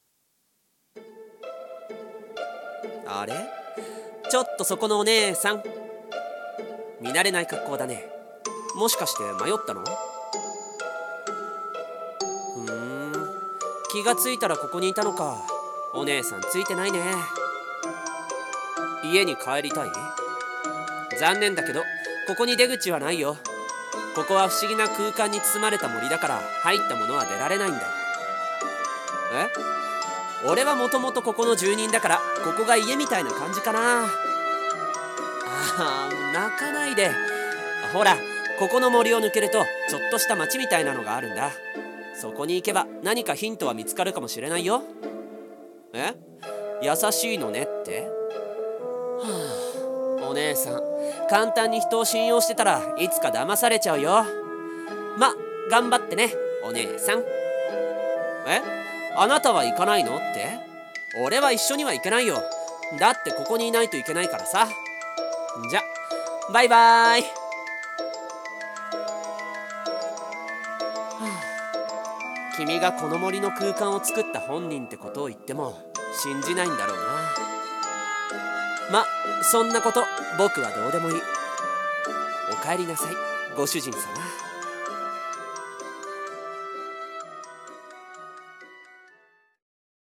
【声劇】不思議な森と住人